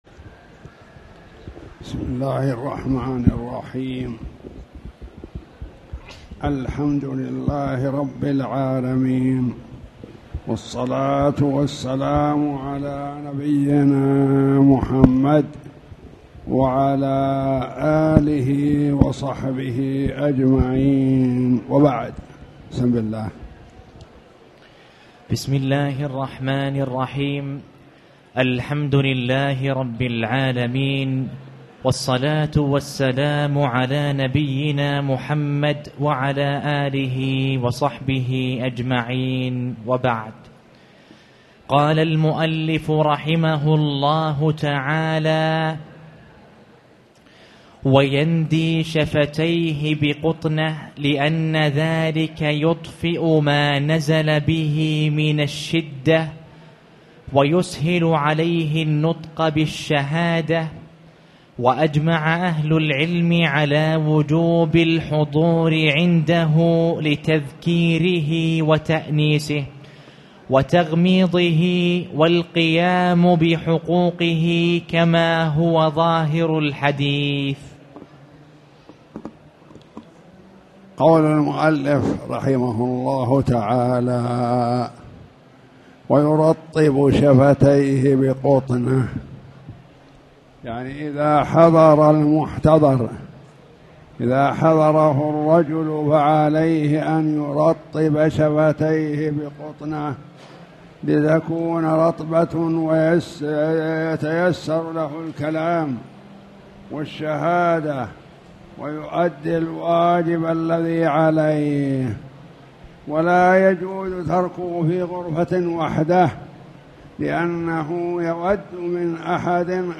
تاريخ النشر ٢٨ شوال ١٤٣٨ هـ المكان: المسجد الحرام الشيخ